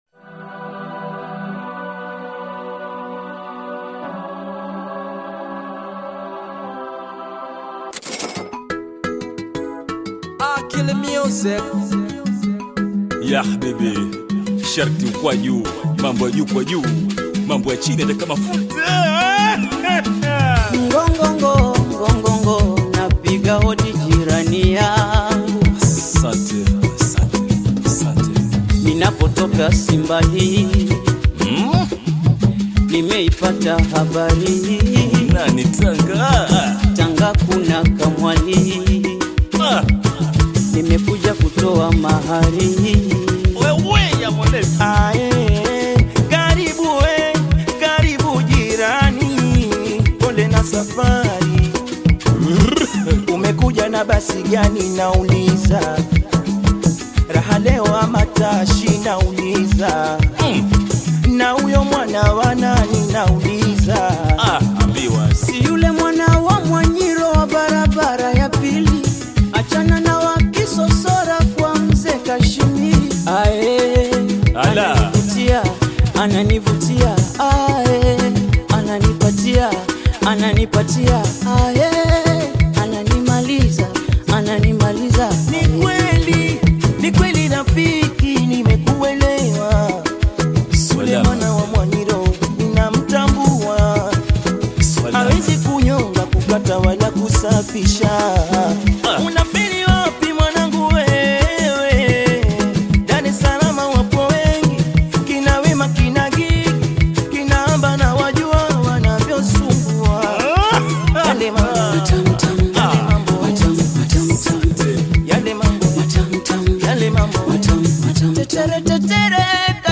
BAIKOKO TANGA Mduara